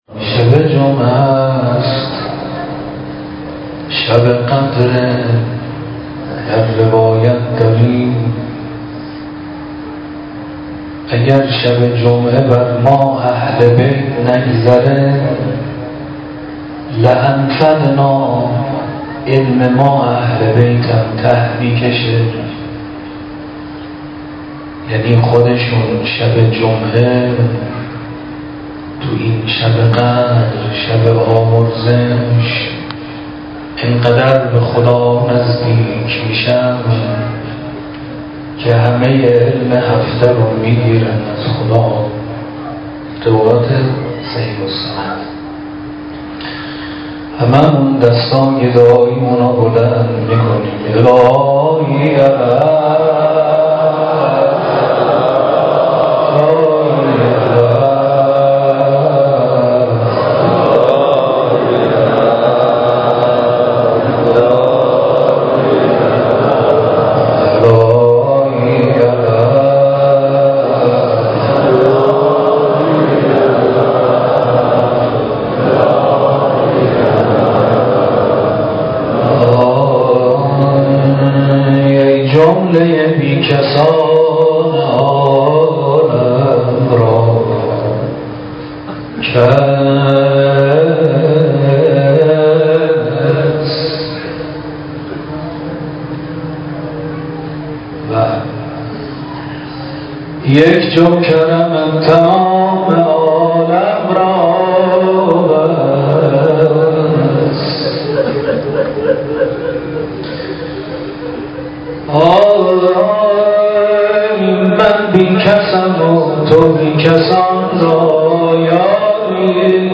صوت مراسم